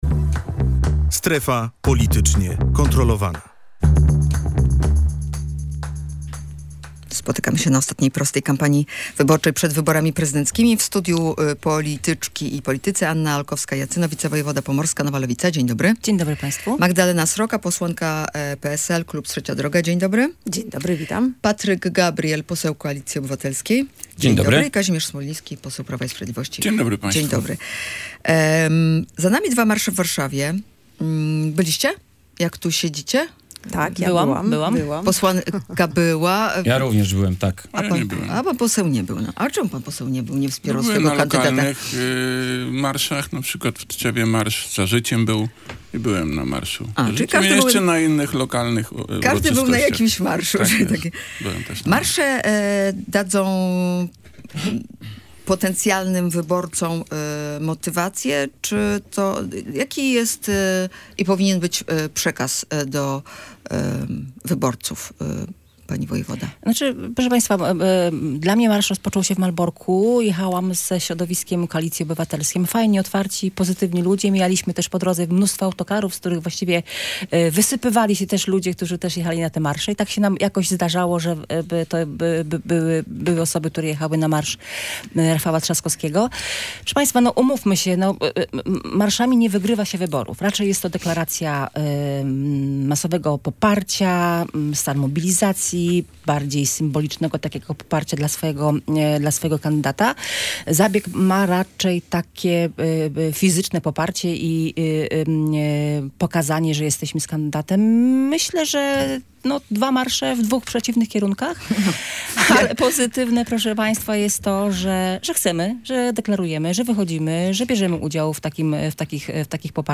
W studiu Radia Gdańsk gościliśmy: Magdalenę Srokę (posłankę PSL-Trzeciej Drogi), Annę Olkowską-Jacyno (wicewojewodę pomorską z Nowej Lewicy), Kazimierza Smolińskiego (posła z Prawa i Sprawiedliwości) i Patryka Gabriela (posła Koalicji Obywatelskiej).